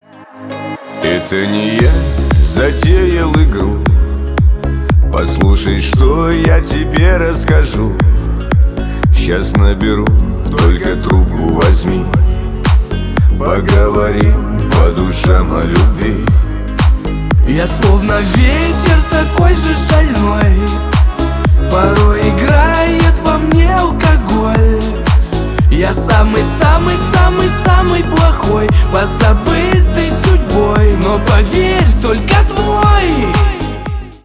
Танцевальные рингтоны , Громкие рингтоны